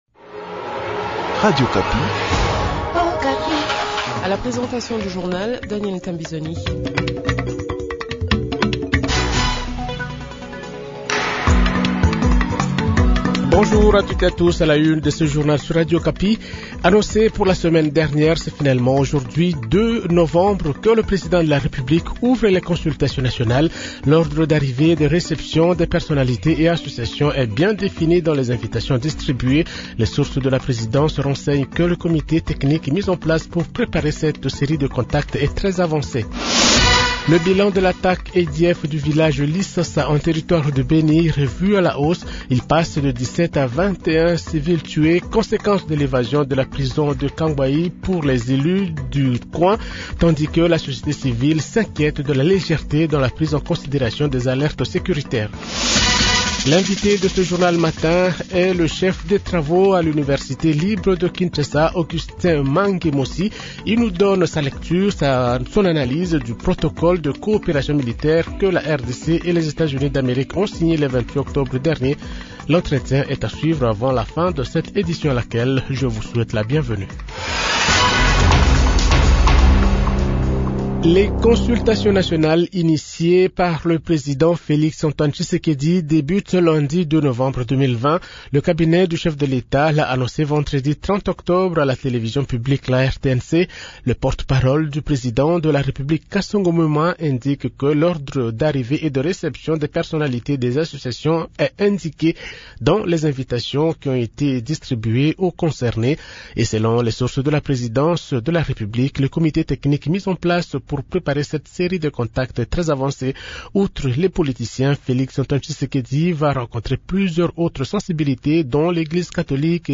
Journal Francais Matin 6h00